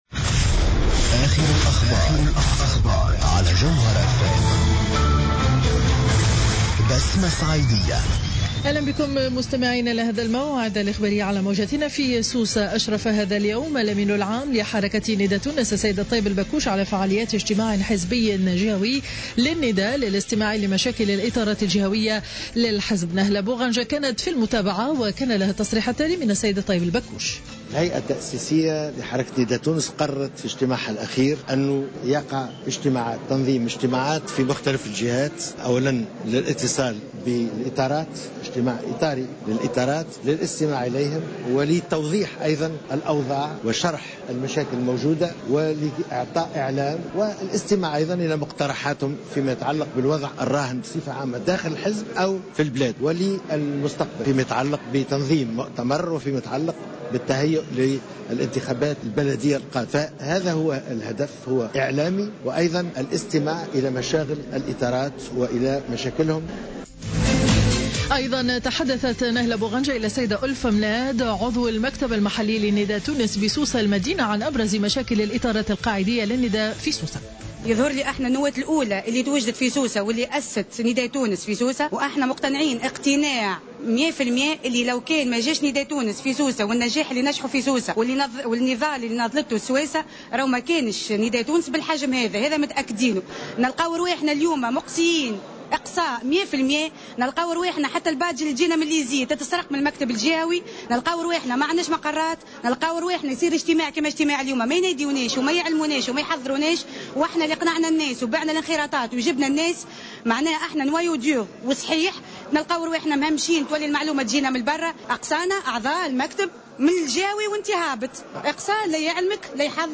نشرة أخبار منتصف النهار ليوم الأحد 22 فيفري 2015